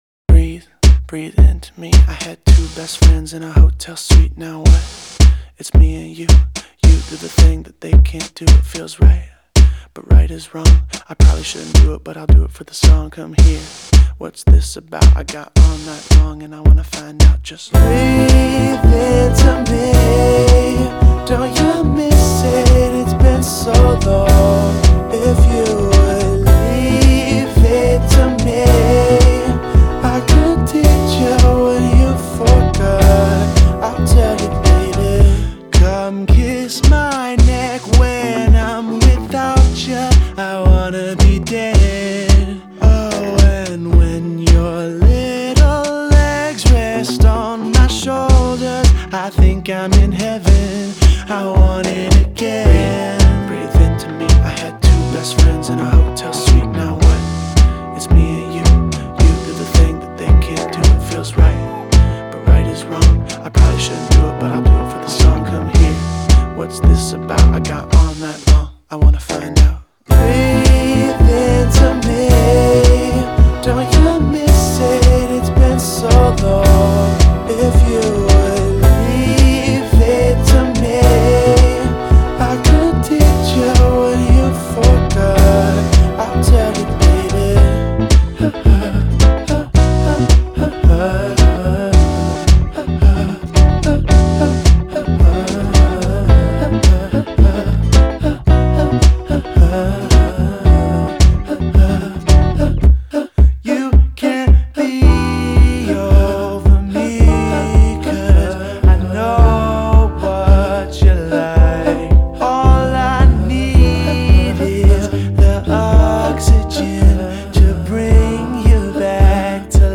Трек размещён в разделе Зарубежная музыка / Альтернатива.